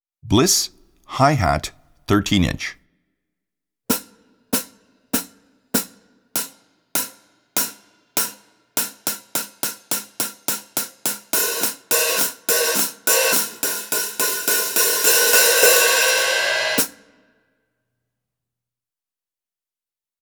Dream 13" BHH13 Bliss Hi Hat Cymbals
They have a warmth that seduces at low volume and thunders with a froth of dark undertones at more extreme volumes.
These cymbals come alive, shimmering and wobbling as if the mere touch of human hands excites the cymbal and it can’t wait to get its voice out there and sing. The undertones are warm, rich, dark with an edge of dragon’s breath.